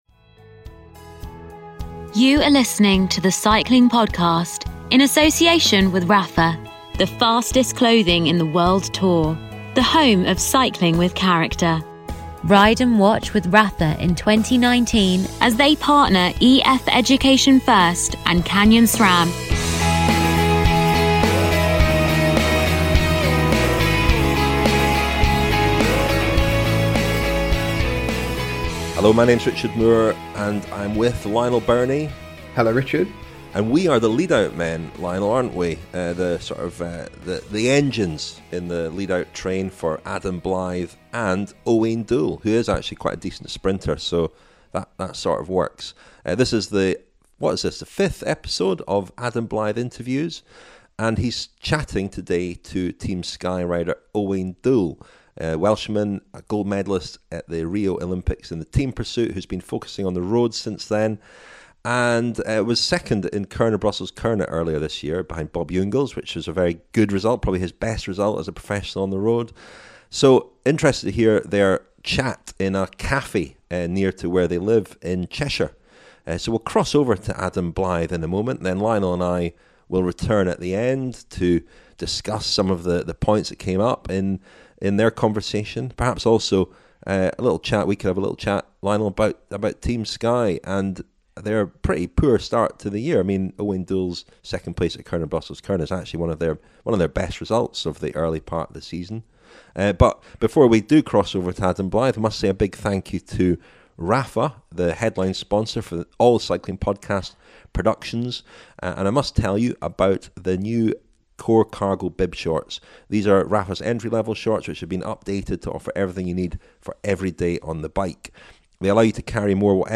Adam Blythe Interviews Owain Doull
In the fifth episode of Adam Blythe Interviews, presented by The Cycling Podcast, Adam meets Team Sky rider Owain Doull.